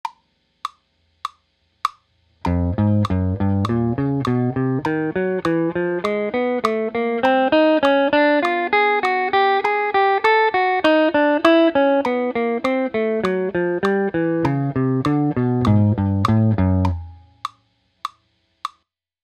This is the third of eight sets of chromatic exercises.